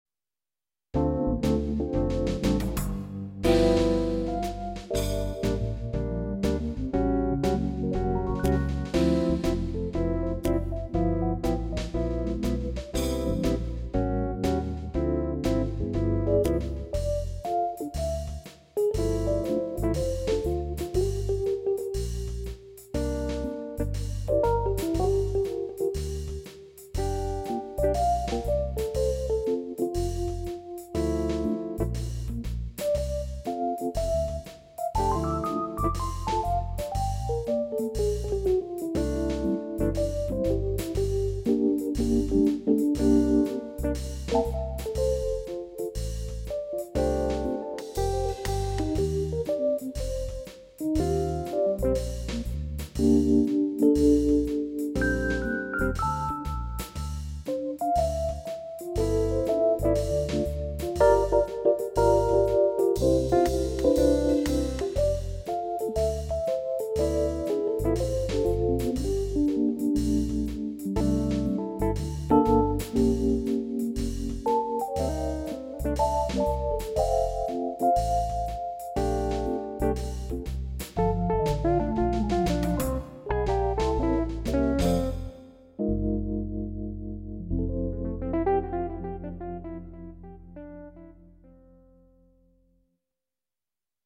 Like sitting in with Jazz trio?
This is one of my custom jazz styles (One of a dozen or so)..They are perfect for that sitting in feel...What makes them work so well...although you may play simple chords, the jazz guitar is playing complex chords...
This style is a basic simple arrangement that allows room to do your own thing (EP in my case here)...
I customize them by changing the drums and instrumentation.. I also change the chords to some bi tonal chords and change the riffs..